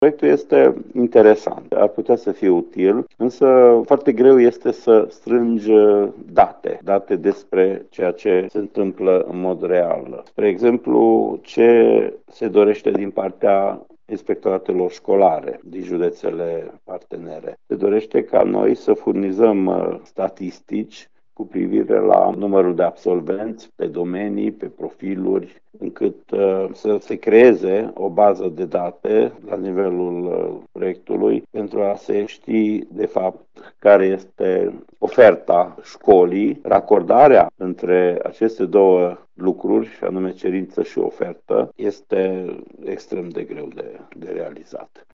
Inspectorul școlar general al județului Mureș, Ștefan Someșan, a salutat utilitatea proiectului, dar a arătat și că obiectivele propuse sunt greu de atins.